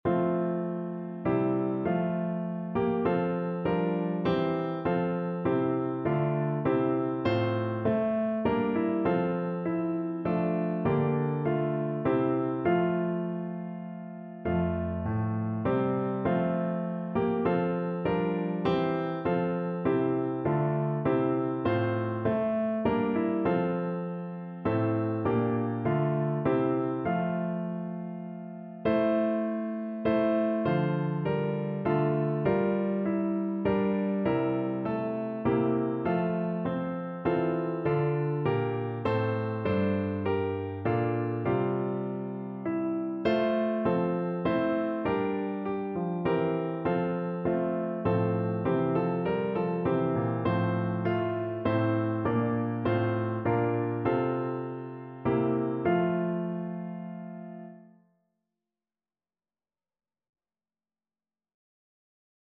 Classical Prichard, Rowland H Alleluia, Sing to Jesus, Hyfrydol Piano version
No parts available for this pieces as it is for solo piano.
F major (Sounding Pitch) (View more F major Music for Piano )
3/4 (View more 3/4 Music)
Piano  (View more Intermediate Piano Music)
Classical (View more Classical Piano Music)